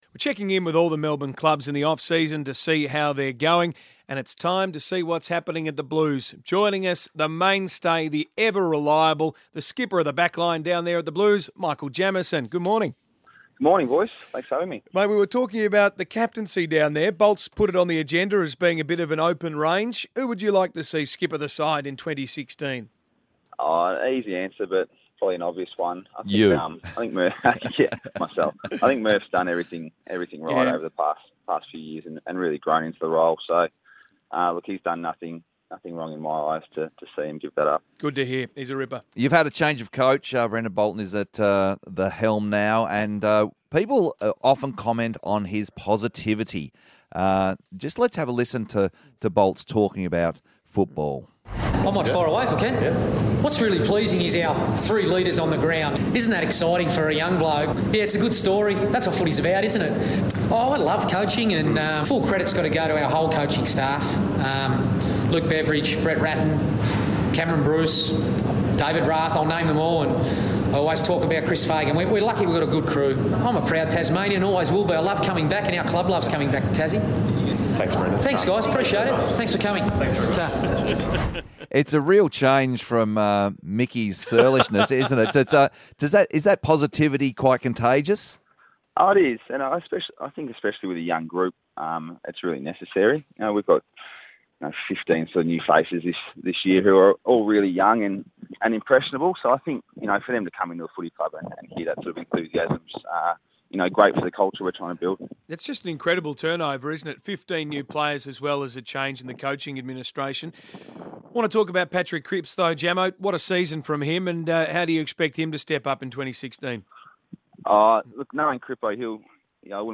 Carlton defender Michael Jamison speaks to Triple M Melbourne ahead of the Blues' pre-season camp on the Gold Coast.